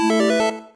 effect / chiptune / connected.ogg